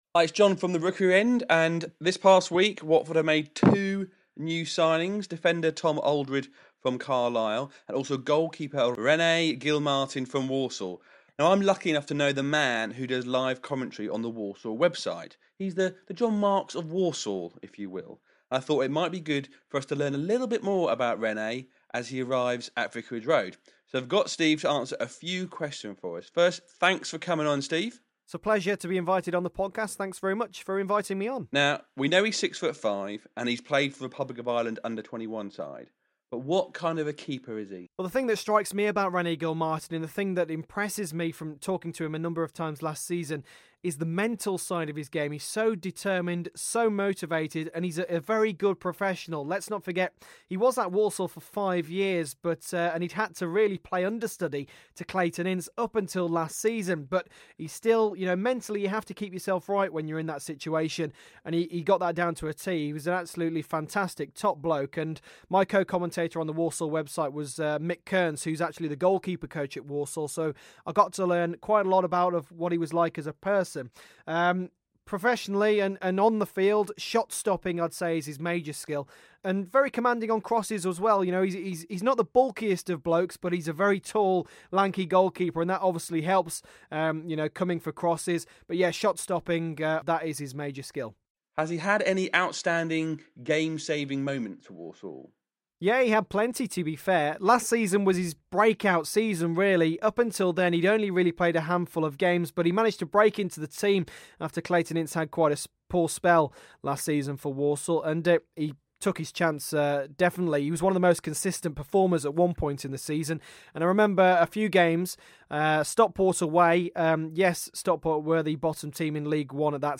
MICRO PODCAST: Chat